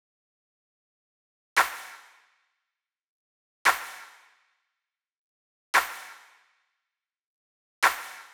10 Clap.wav